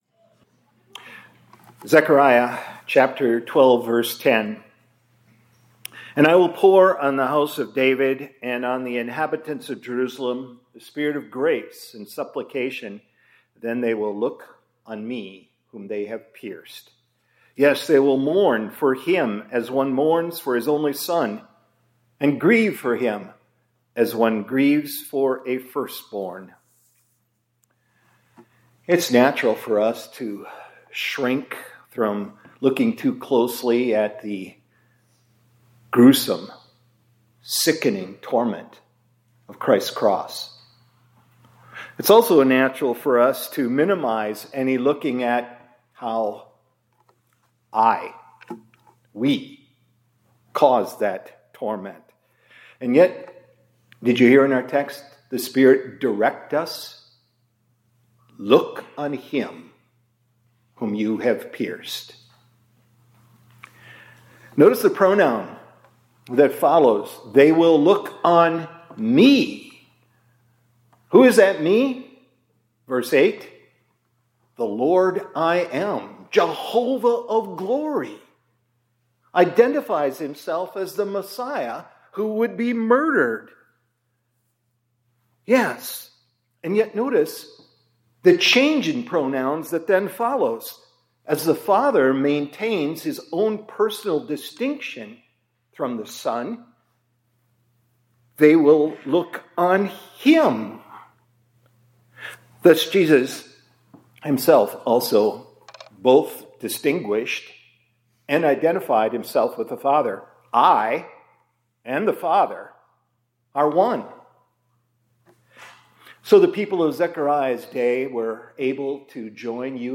2026-02-25 ILC Chapel — Look On Him Whom We Have Pierced